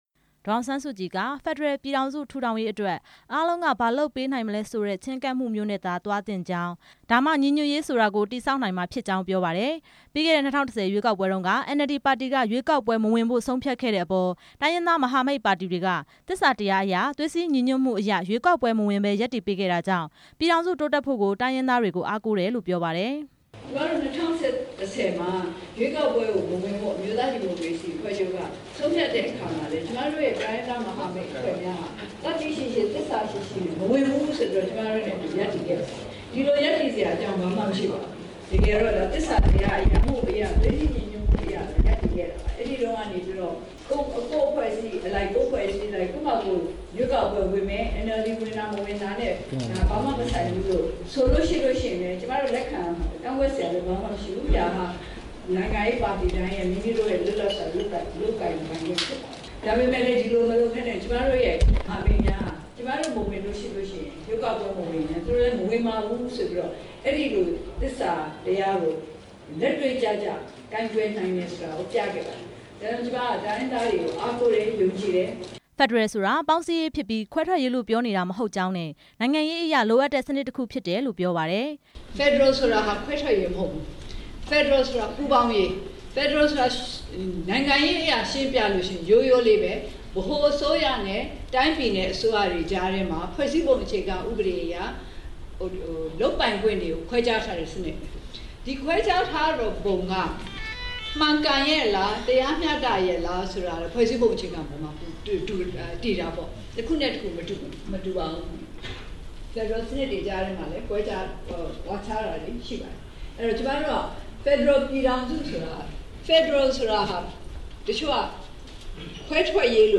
ဒီကနေ့ နေပြည်တော် စည်ပင်ဧည့်ရိပ်သာမှာ တိုင်းရင်းသား လွှတ်တော်ကိုယ်စားလှယ်တွေနဲ့ တွေ့ဆုံပွဲ မှာ ဒေါ်အောင်ဆန်းစုကြည်က အဖွင့်မိန့်ခွန်းပြောကြားစဉ် ထည့်သွင်းပြောတာ ဖြစ်ပါတယ်။